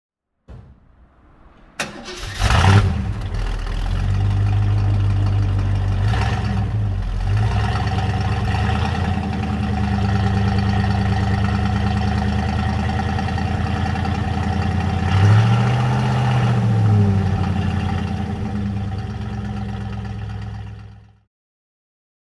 Ford Mustang (1964) - Starten und Leerlauf
Ford_Mustang_1964.mp3